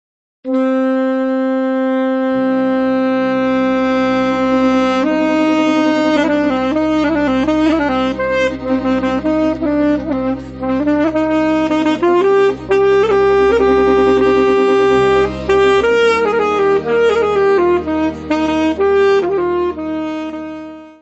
traditional Yiddish music
violino
clarinete
saxofone
acordeão
tuba
tapan, darabukka.
Music Category/Genre:  World and Traditional Music